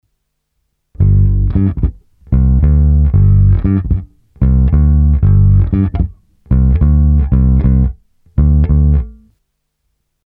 Nejdřív jen stejné kolečko samotného tracku basy:
Fender Precision Bass